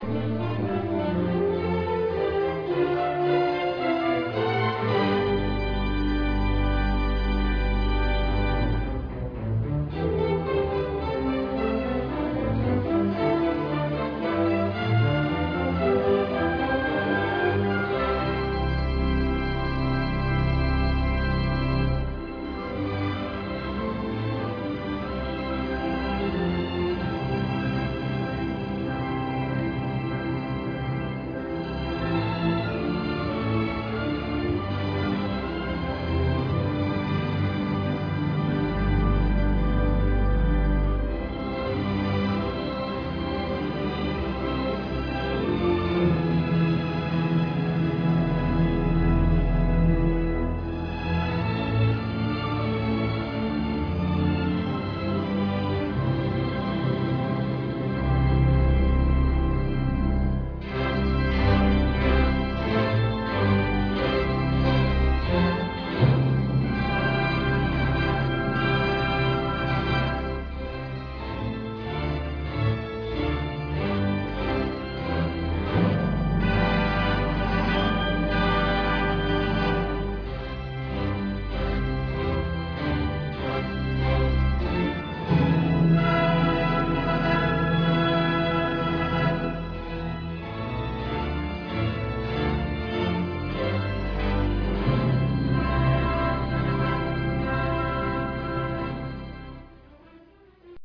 Orchestre symphonique en tournée
Nous vous souhaitons beaucoup de plaisir avec nos jeunes musiciens leur de leur dernière tounée en France (1996).